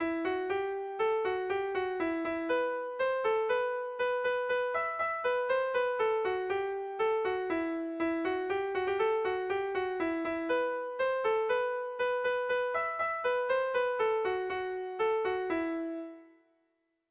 Irrizkoa
Lauko txikia (hg) / Bi puntuko txikia (ip)
AB